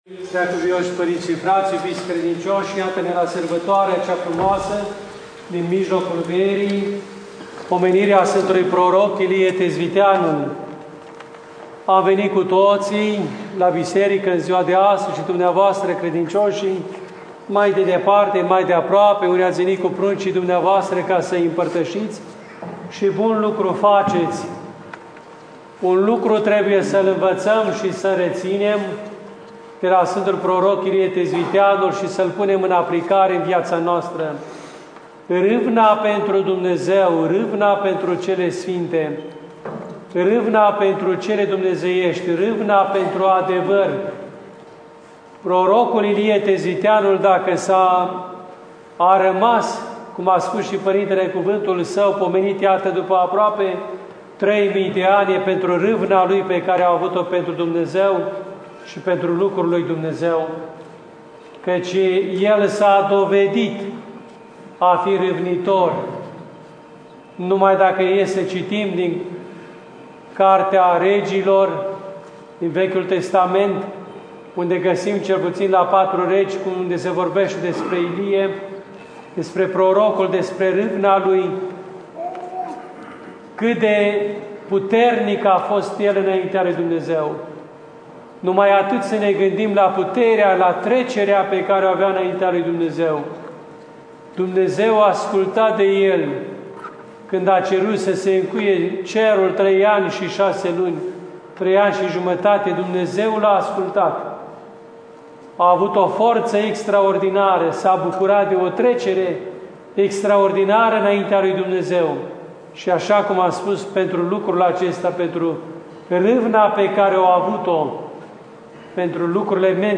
Arhivă de predici la Sf. Ilie / ortodoxradio